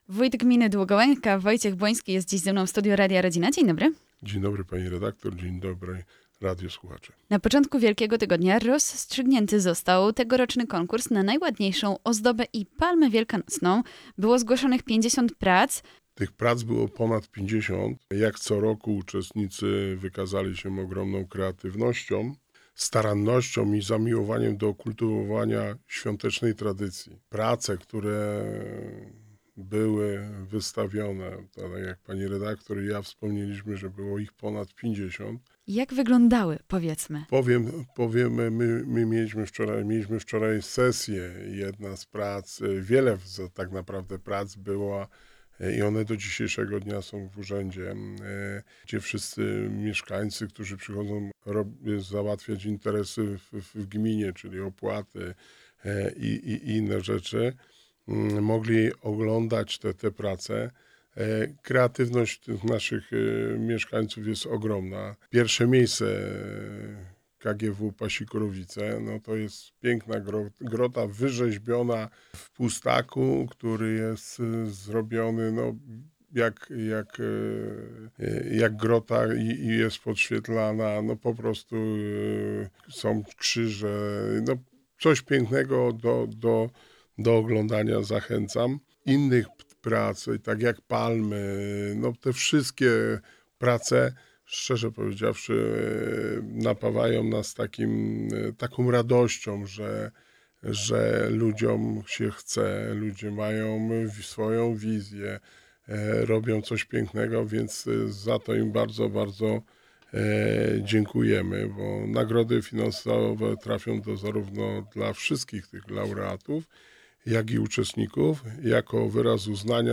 Wojciech Błoński wójt gminy Długołęka